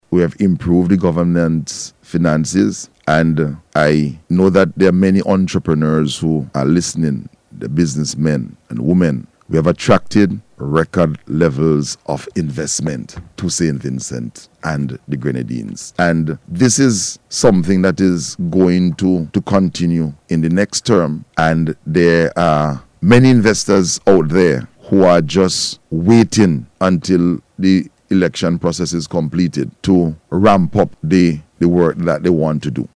Minister of Agriculture, Forestry and Fisheries, Saboto Caesar made this statement during the Face to Face programme aired on NBC Radio yesterday.